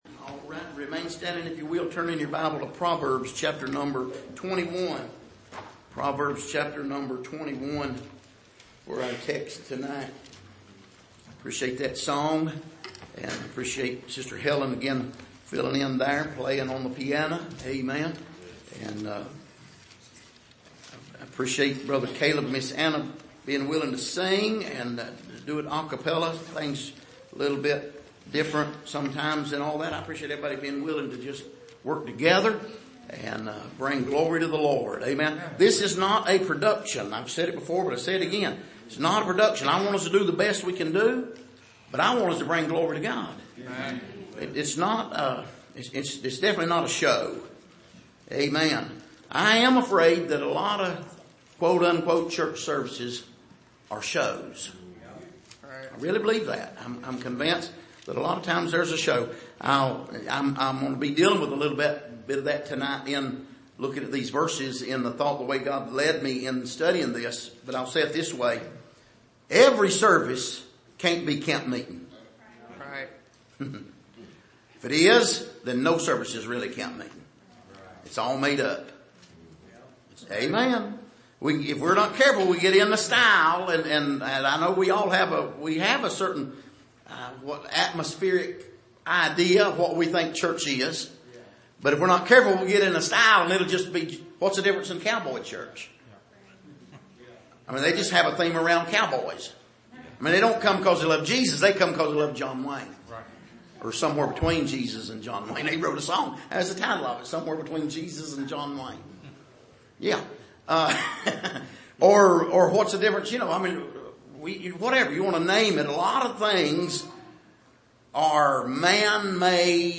Passage: Proverbs 21:1-5 Service: Sunday Evening